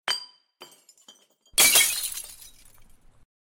zvuk_butilky.ogg